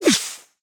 Minecraft Version Minecraft Version 1.21.5 Latest Release | Latest Snapshot 1.21.5 / assets / minecraft / sounds / entity / pufferfish / blow_out2.ogg Compare With Compare With Latest Release | Latest Snapshot
blow_out2.ogg